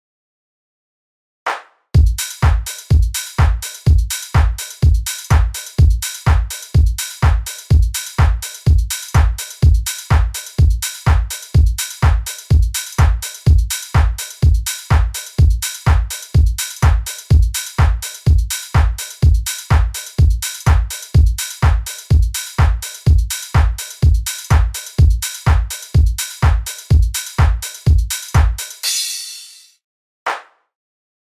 Chorus/Drop:
This creates a ‘skippy’ feeling that is used a lot in garage/house music to create an interesting rhythm.
Here is a bounce of the drums depicted here, with no FX or automation: